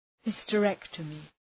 {,hıstə’rektəmı}